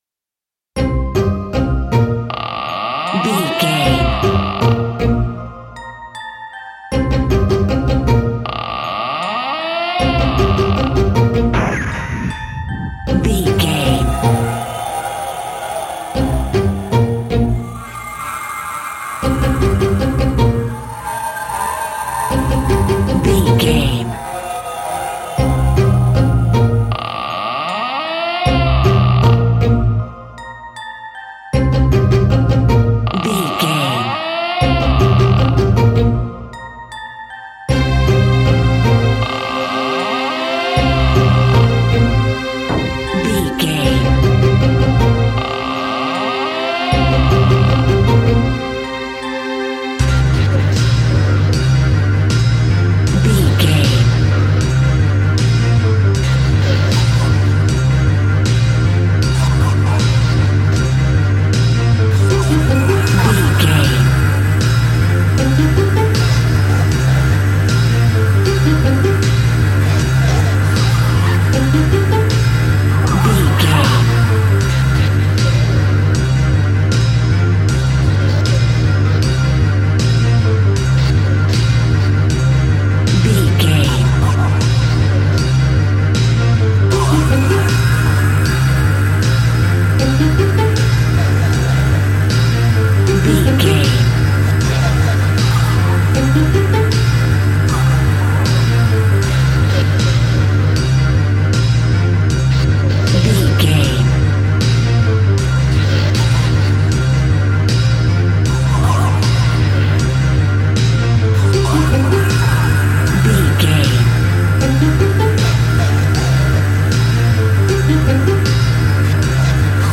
Aeolian/Minor
ominous
eerie
strings
percussion
electric guitar
drums
harp
horror music
horror piano
Horror Synths